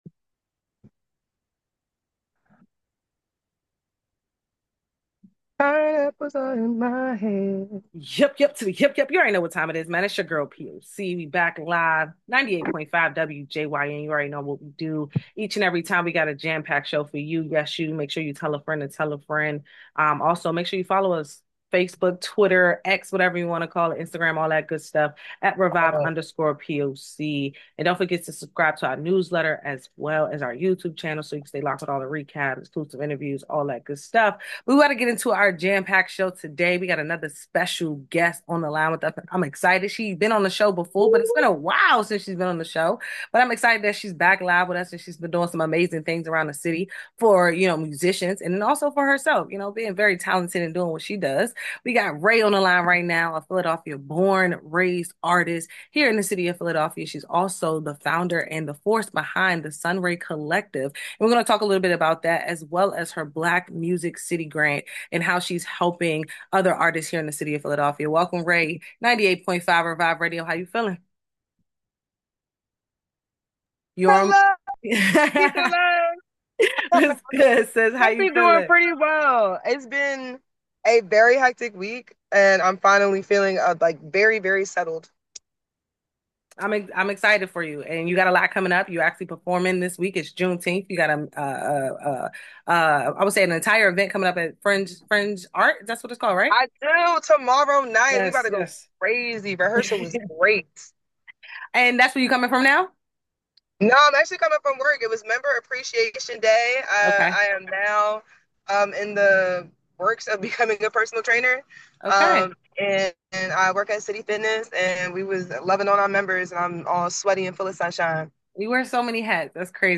During our recent interview on WJYN 98.5 FM